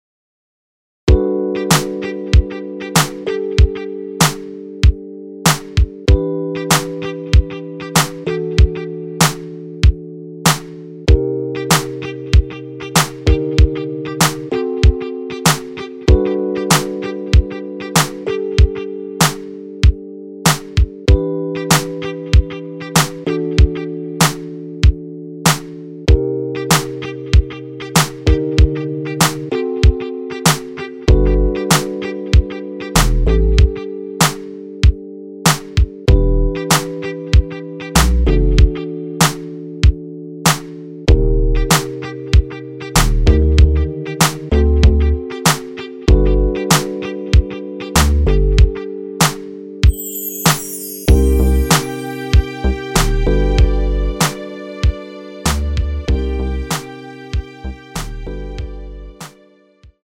엔딩이 페이드 아웃이라 라이브 하시기 좋게 엔딩을 만들어 놓았습니다.(멜로디 MR 미리듣기 참조)
원키에서(+5)올린 MR입니다.
앞부분30초, 뒷부분30초씩 편집해서 올려 드리고 있습니다.
중간에 음이 끈어지고 다시 나오는 이유는